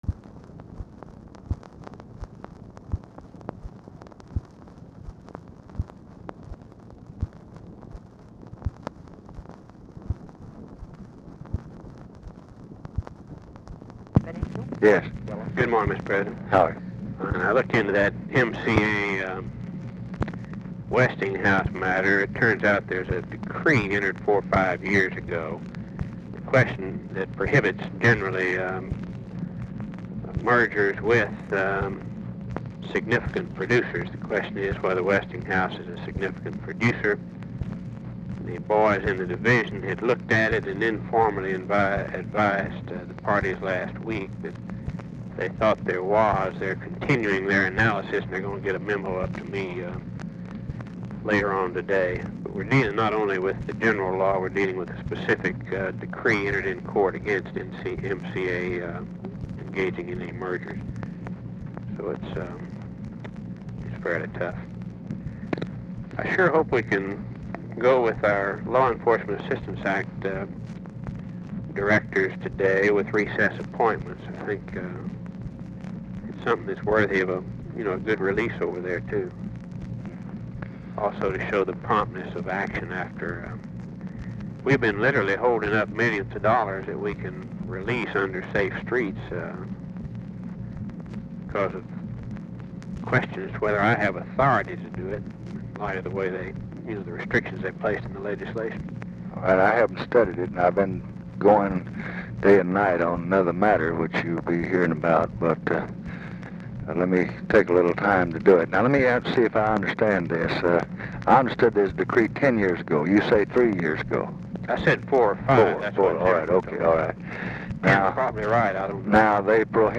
Mansion, White House, Washington, DC
Telephone conversation
Dictation belt